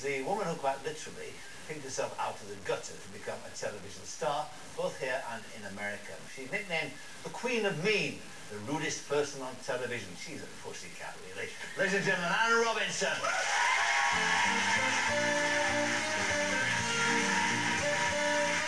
HERE ARE SOME OTHER SOUNDS LIKE INTERVIEWS OF WL HOSTS!
Parky Introducing Anne